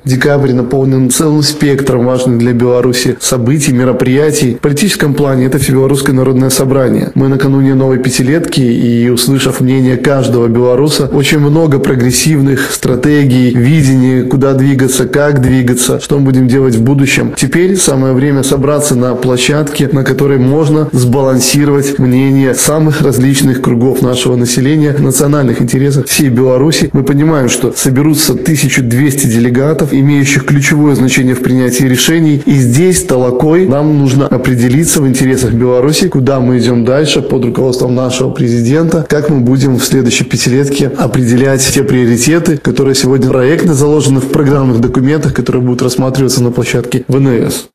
Своим мнением о роли ВНС поделился депутат Палаты представителей Национального собрания Павел Попко.